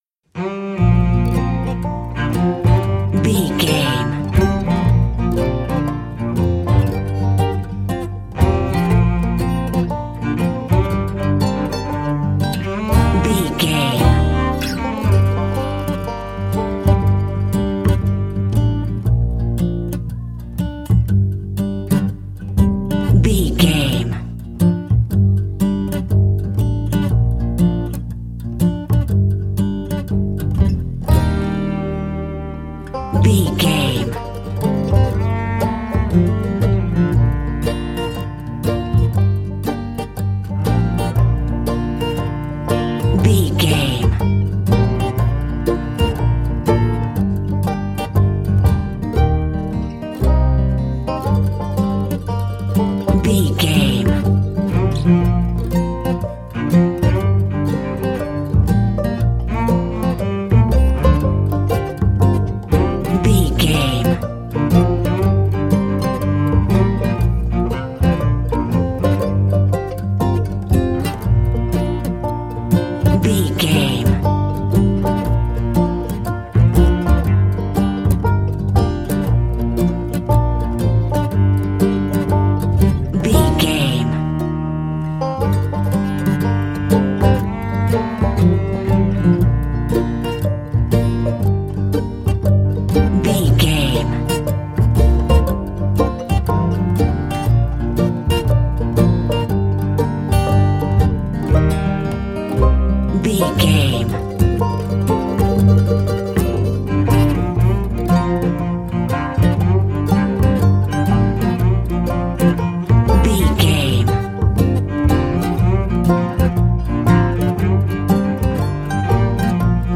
Ionian/Major
B♭
acoustic guitar
bass guitar
cello
banjo
country rock
bluegrass
uplifting
driving
high energy